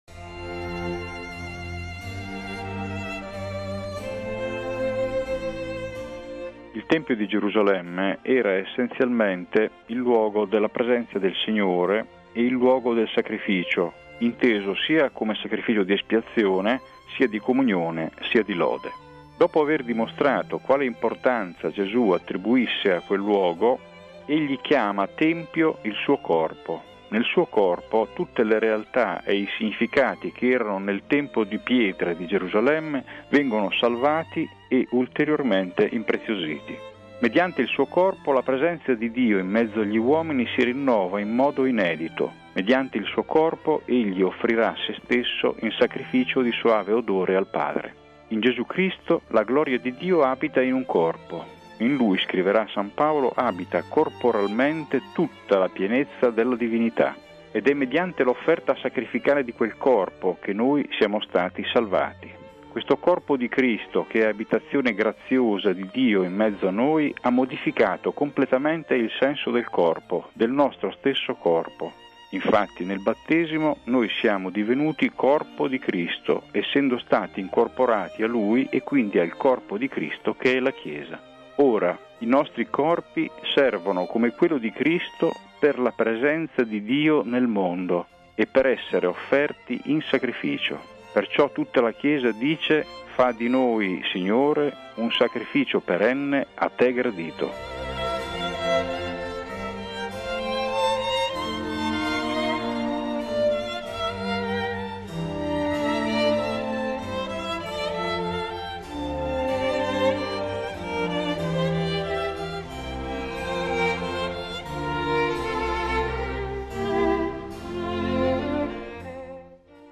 Su questo brano evangelico, ascoltiamo il commento del teologo